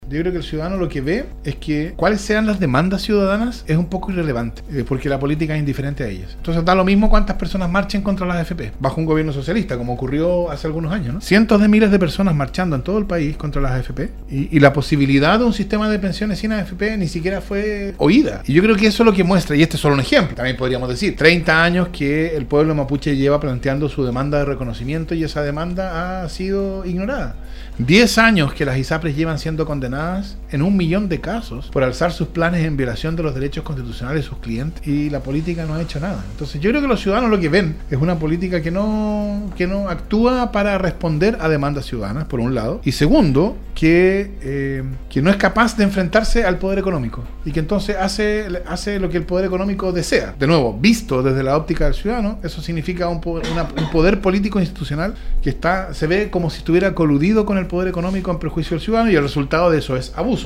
El abogado, docente universitario y político, Fernando Atria, estuvo en los estudios de Nostálgica la tarde de este viernes donde se refirió al estallido social que se vive en el país desde el pasado mes de octubre del 2019.